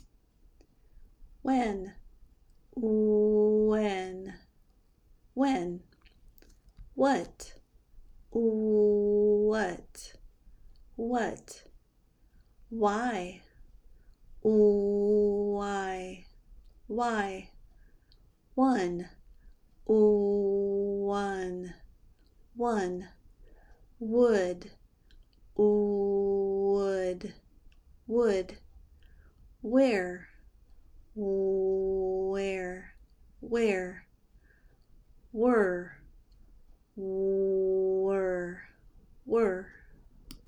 Pronounce W in American English
Here are some more words that begin with the w sound.